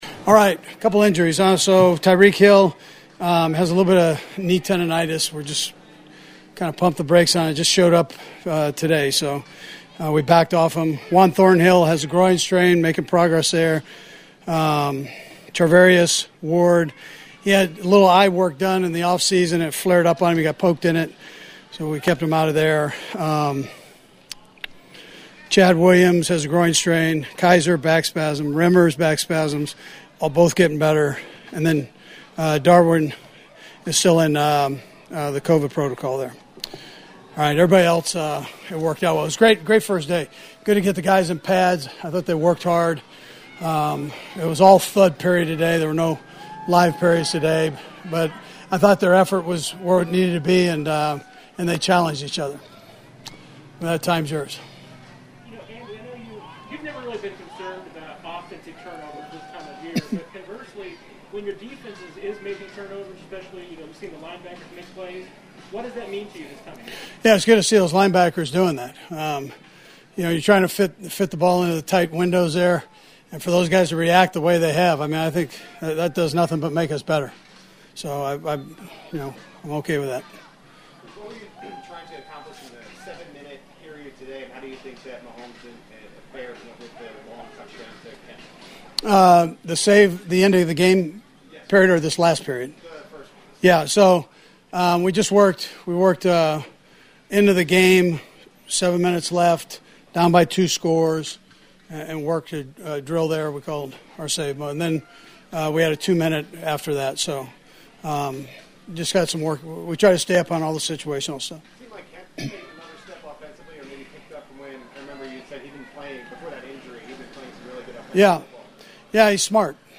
Coach Andy Reid visits with the media after Tuesday’s practice.